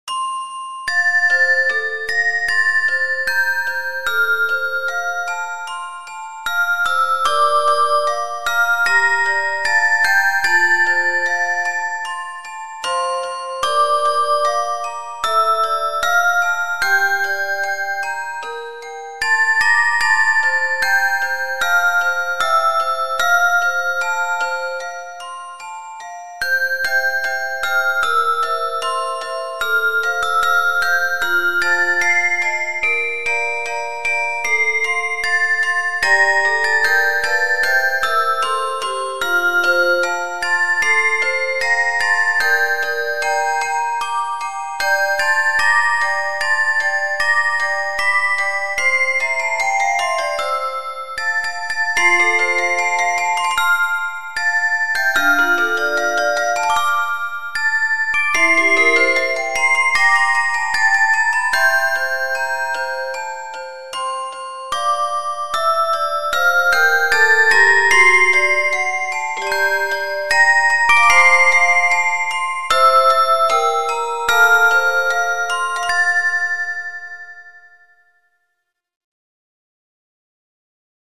♪校歌合唱(mp3)